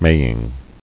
[may·ing]